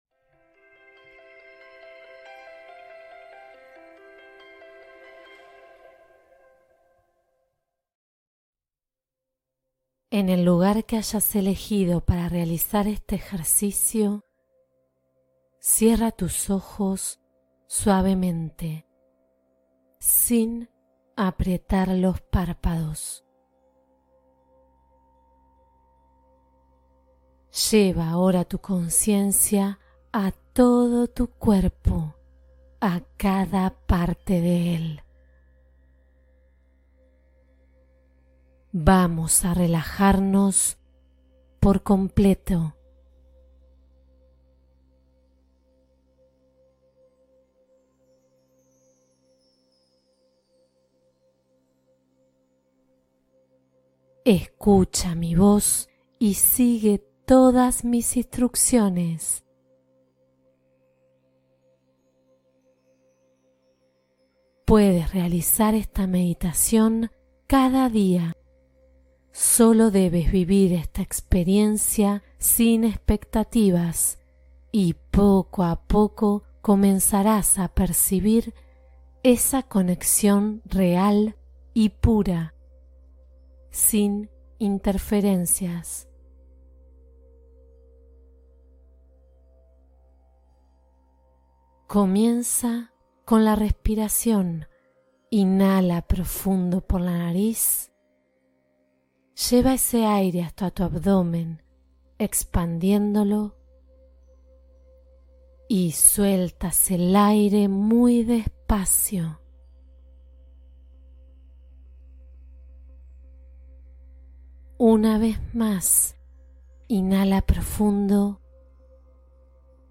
Conecta con tu yo superior con esta meditación mindfulness transformadora